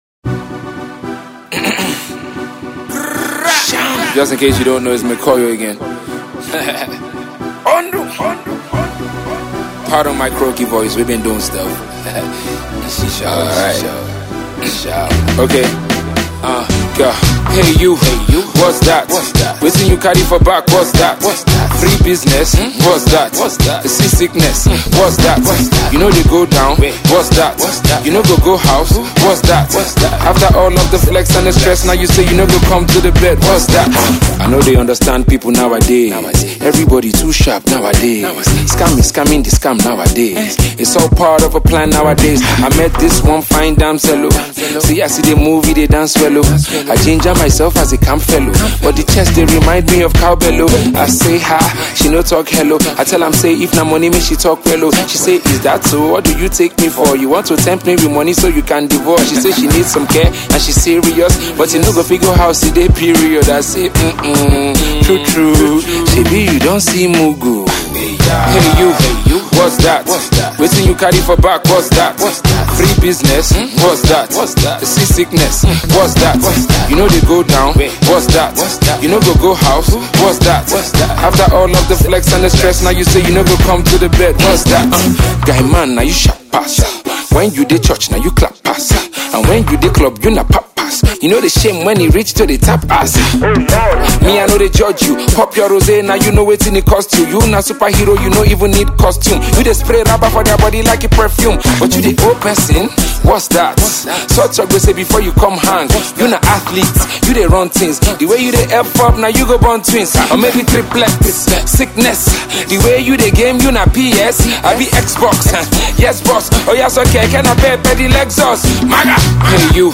This is quite a groovy one!